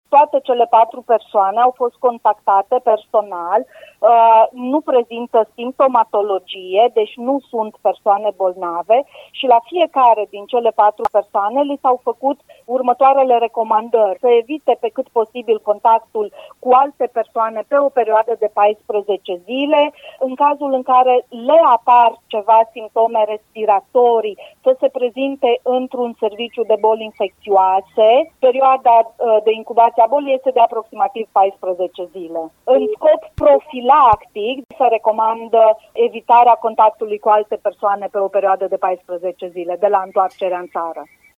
Medic epidemiolog